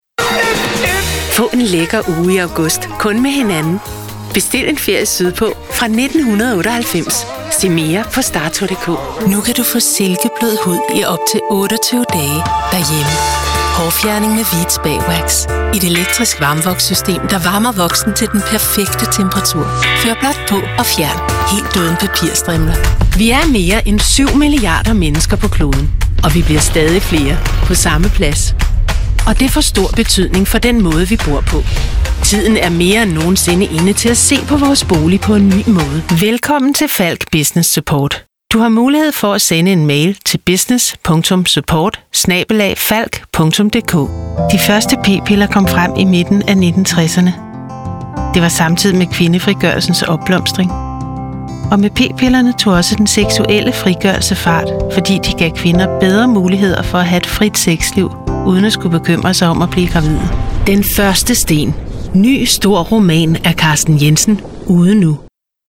Experienced female danish voiceover with warm, thrustworthy voice with a natural smile in it
Sprechprobe: Sonstiges (Muttersprache):
I specialize in accent-free Danish voiceover for all media and have my own professional recordingstudio.